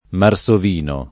vai all'elenco alfabetico delle voci ingrandisci il carattere 100% rimpicciolisci il carattere stampa invia tramite posta elettronica codividi su Facebook marsovino [ mar S ov & no ] o marsuino [ mar S u- & no ] s. m. (zool.)